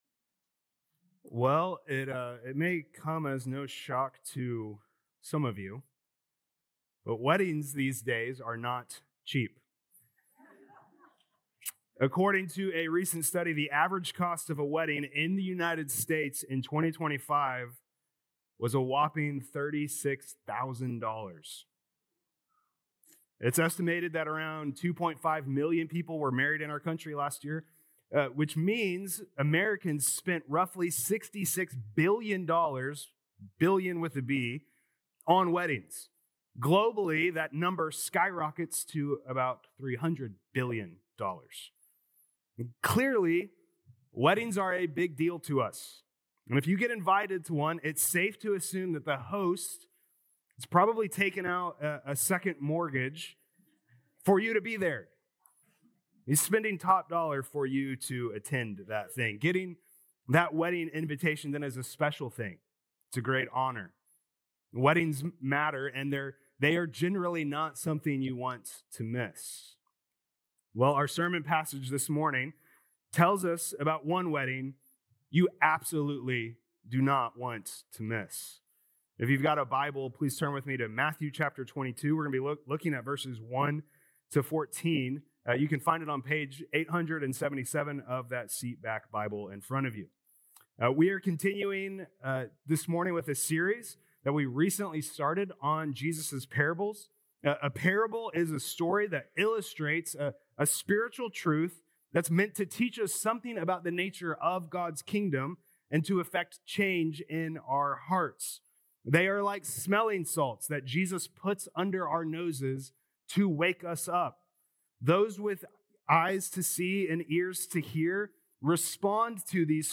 Feb 8th Sermon | Matthew 22:1-14